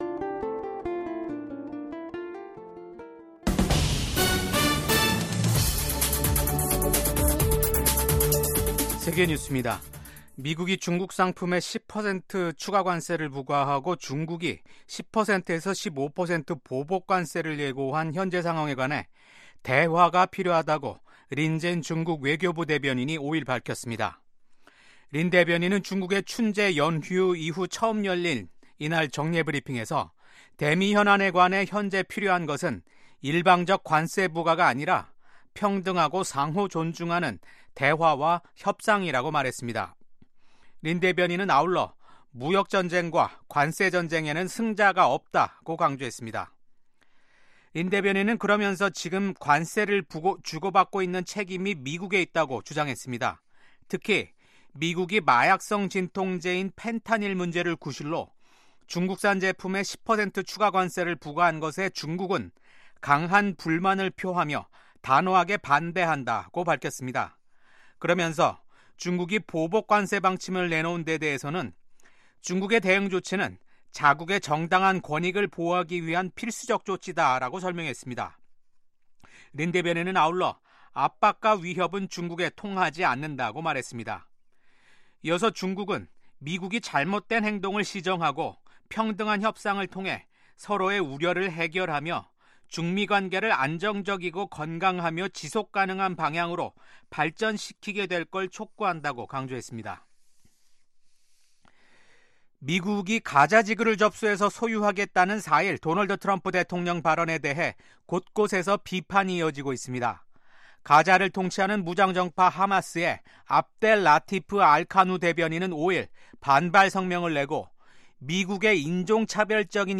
VOA 한국어 아침 뉴스 프로그램 '워싱턴 뉴스 광장'입니다. 러시아에 파견돼 상당수 사상자가 발생한 북한 군이 쿠르스크 전선에서 최근 일시 퇴각했다는 소식이 전해지면서 추가 파병이 임박한 게 아니냐는 관측이 나오고 있습니다. 북대서양조약기구(나토. NATO)가 북한의 러시아 파병 증원설과 관련해 양국에 국제법 위반 행위를 즉각 중단할 것을 촉구했습니다.